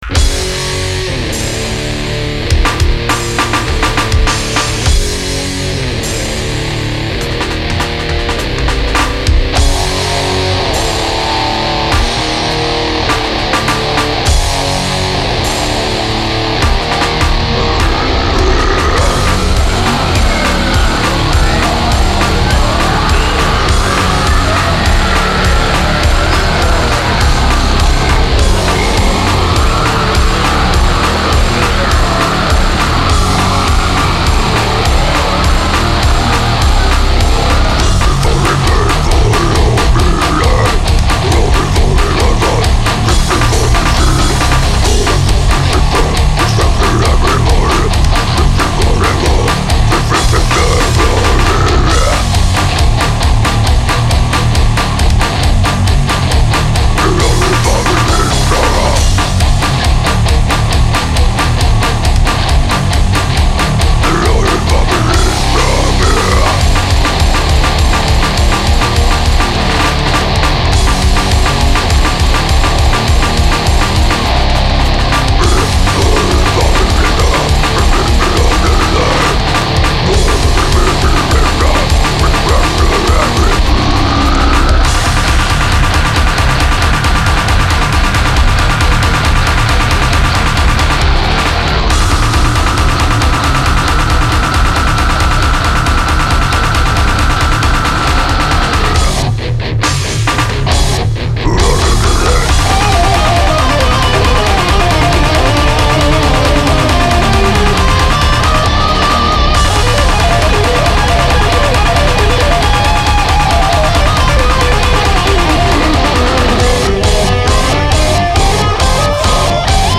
basses, guitars
vocals
drums
lead guitar